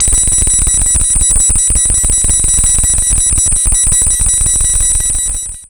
56.3 SFX.wav